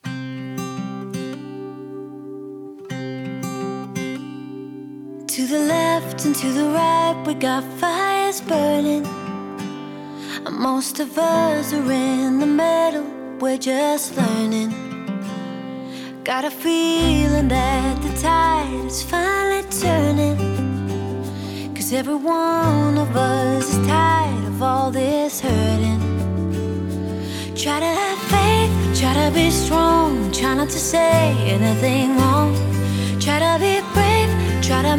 # Поп